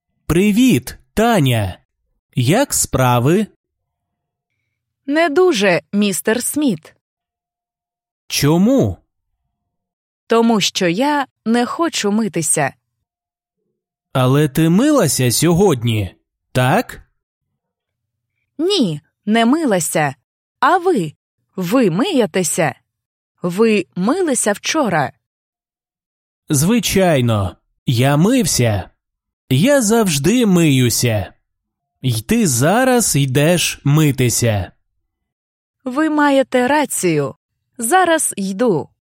Dialogues
basic-ukrainian-lesson-07-dialogue-03.mp3